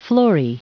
Prononciation du mot florae en anglais (fichier audio)
Prononciation du mot : florae